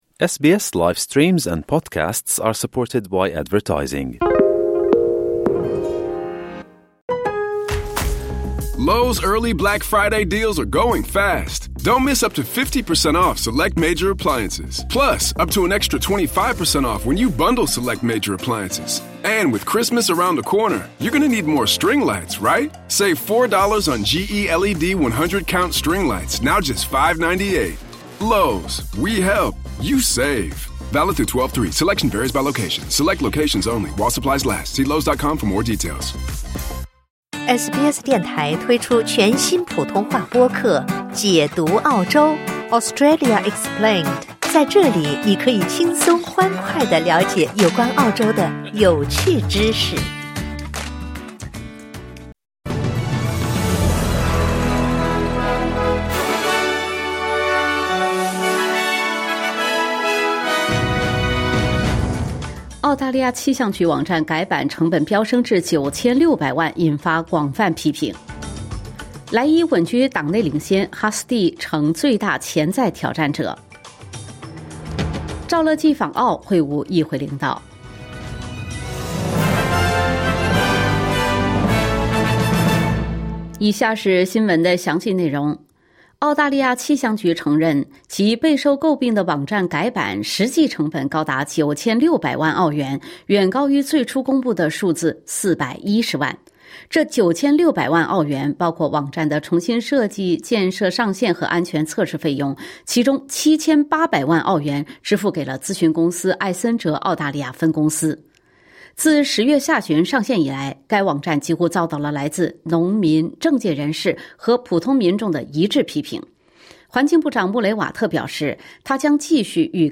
SBS早新闻（2025年11月24日）